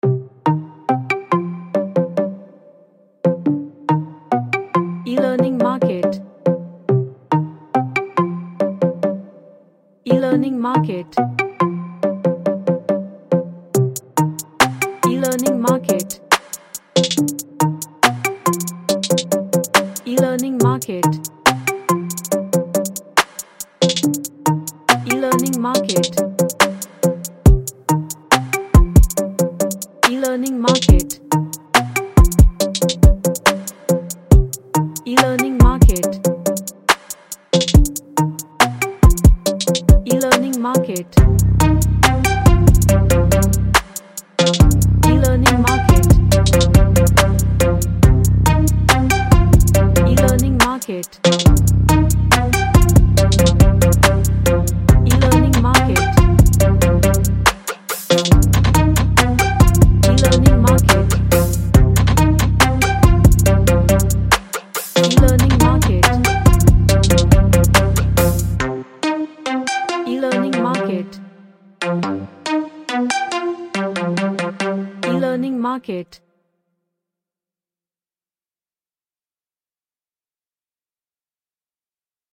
A tight sounding hip hop track
Dark / Somber